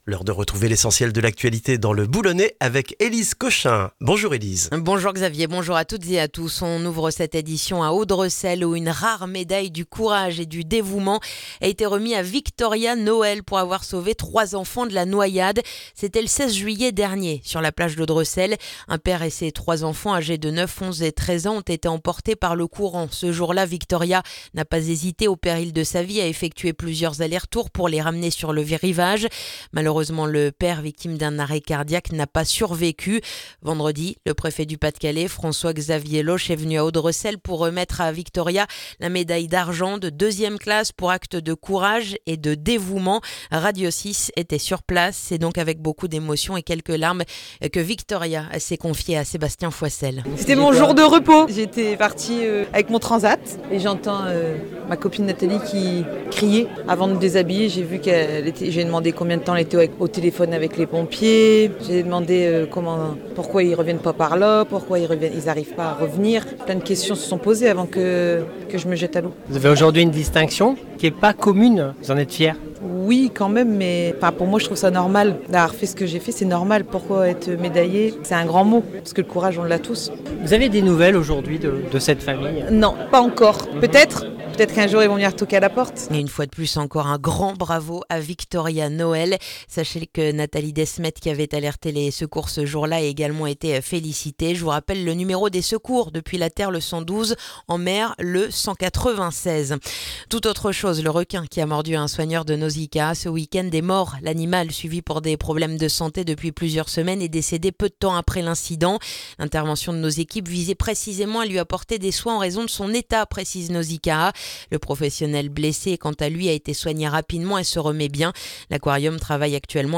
Le journal du mardi 27 janvier dans le boulonnais